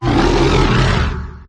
grimmsnarl_ambient.ogg